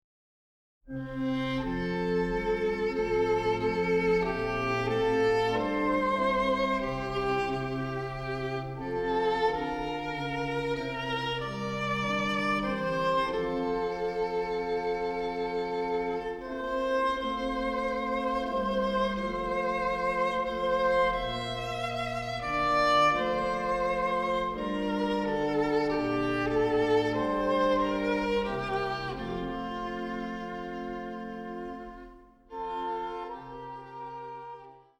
Männerchor
• die Lieder werden mit besonderer Emotionalität vorgetragen